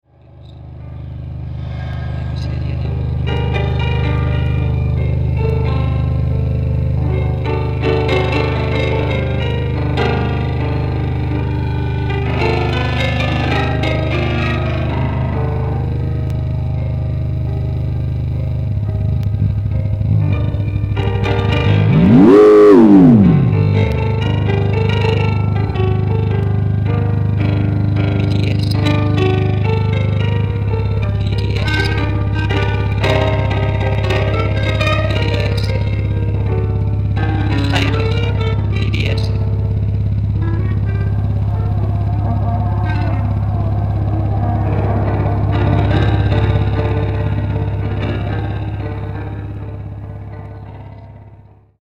謎な騒音あれやこれや。
即興